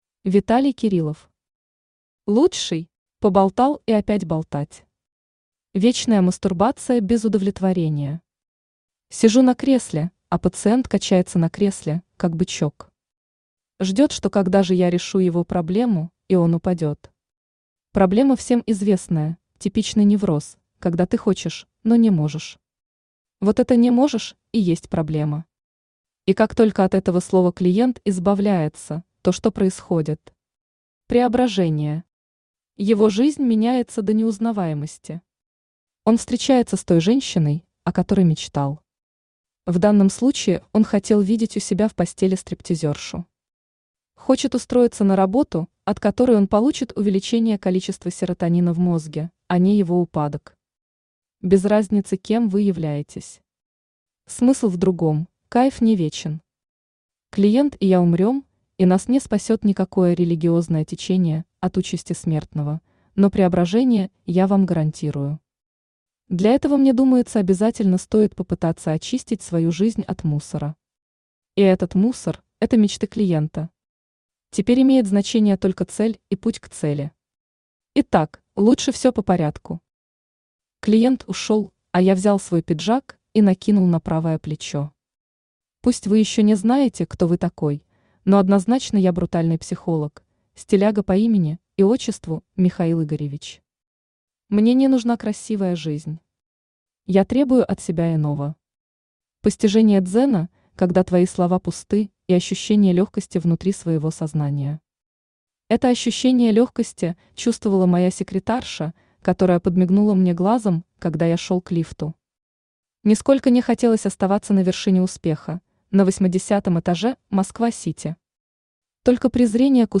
Аудиокнига Лучший | Библиотека аудиокниг
Aудиокнига Лучший Автор Виталий Александрович Кириллов Читает аудиокнигу Авточтец ЛитРес.